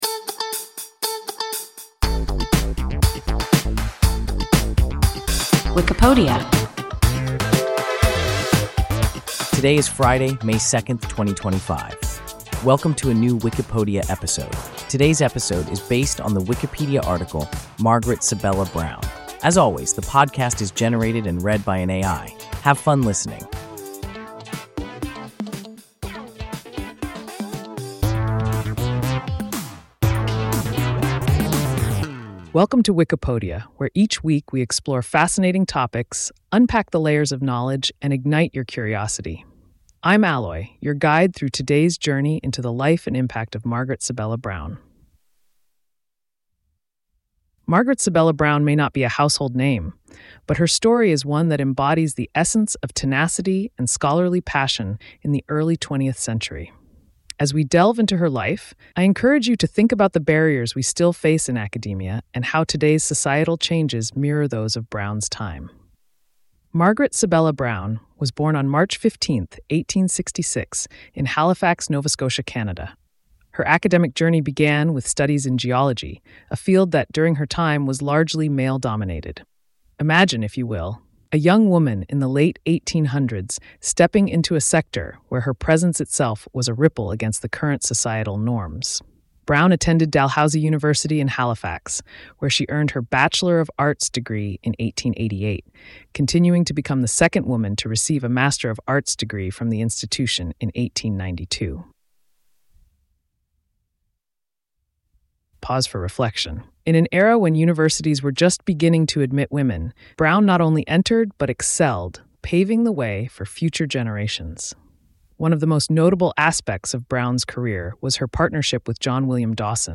Margaret Sibella Brown – WIKIPODIA – ein KI Podcast